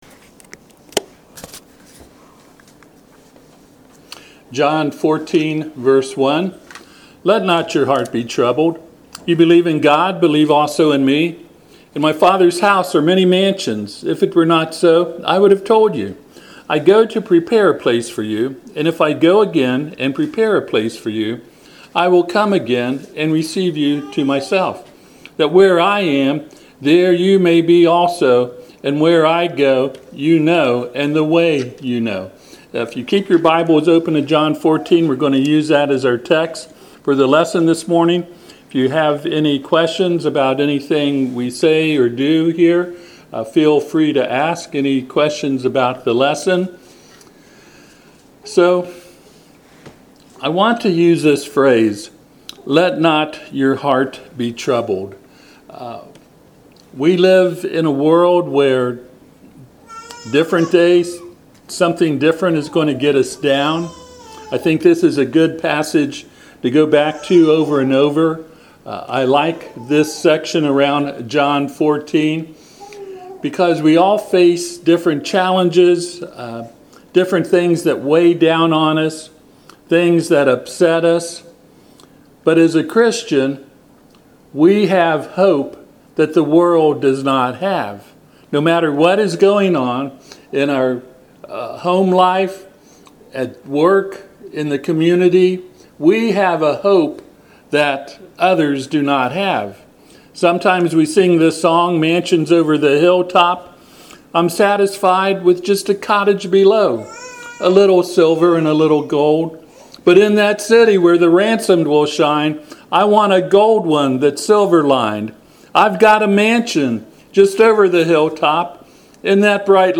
Passage: John 14:1 Service Type: Sunday AM